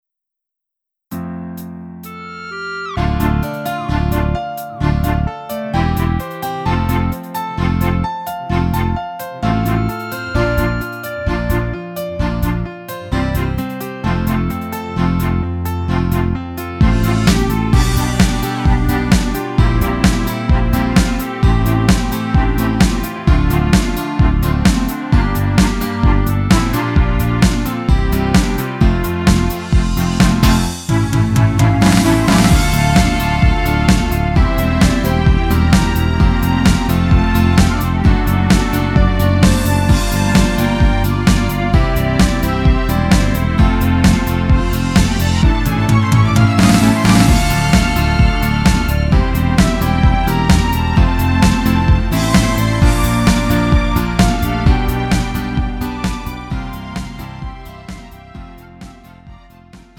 음정 원키 3:26
장르 가요 구분 Lite MR